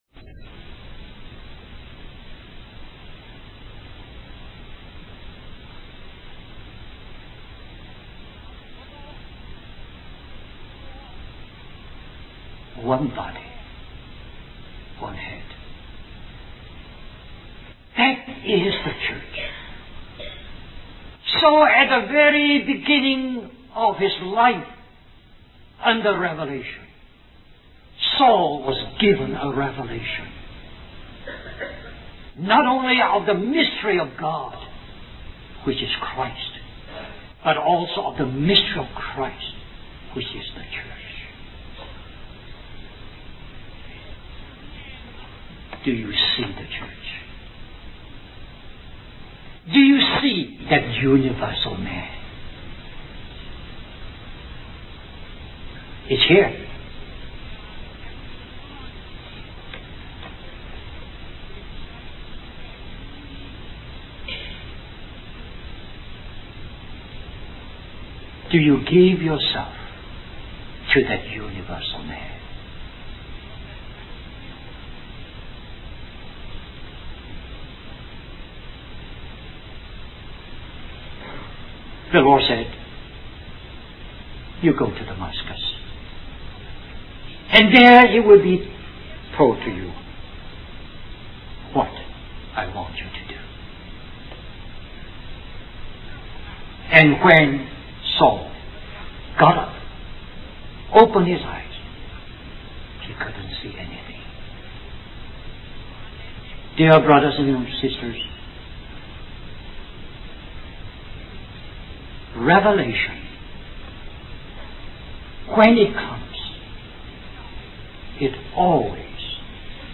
1994 Christian Family Conference Stream or download mp3 Summary This message is a contination of the message found here .